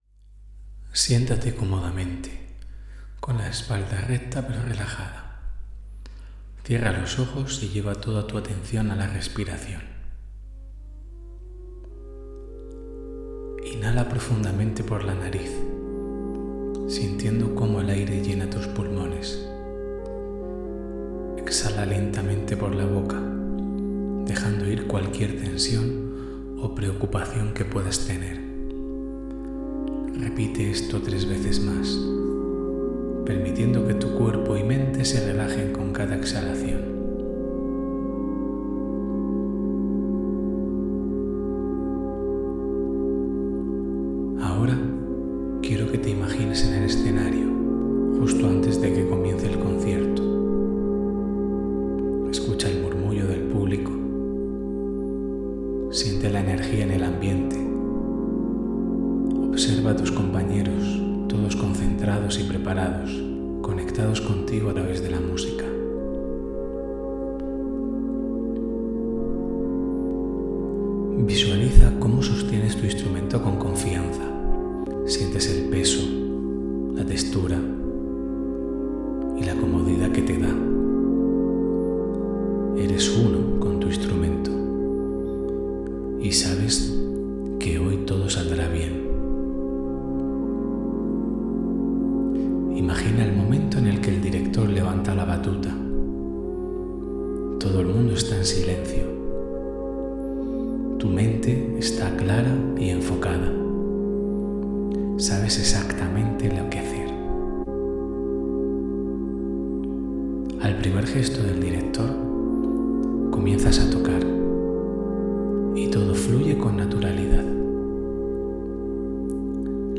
Para ayudarte a implementar esta técnica en tus ensayos, he creado una visualización guiada que puedes utilizar con tus músicos antes de un concierto. Este ejercicio no solo prepara mentalmente a cada miembro de la banda, sino que también fortalece la cohesión y la conexión emocional entre ellos, lo que se traduce en una actuación más potente y unida.